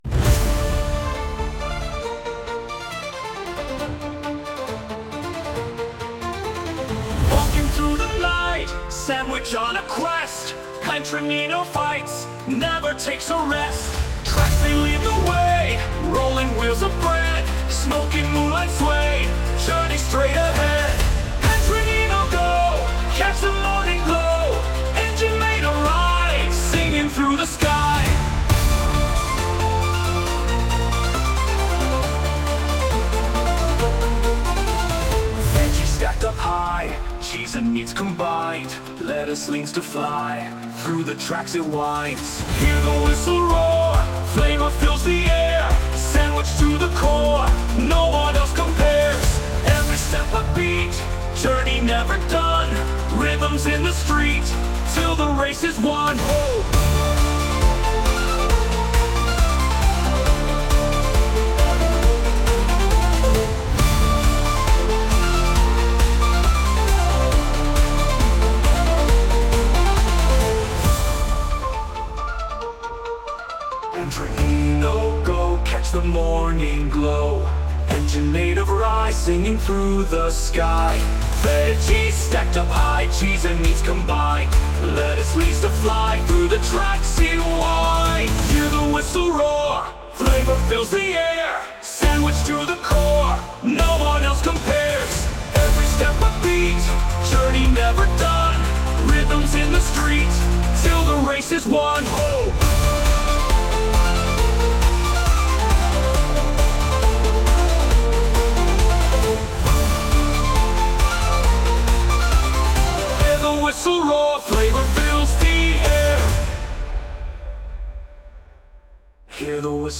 Gli studenti hanno sperimentato con suoni che potessero sembrare alieni e non convenzionali, ma che al contempo riflettessero il tono emotivo della storia.
Possibili colonne sonore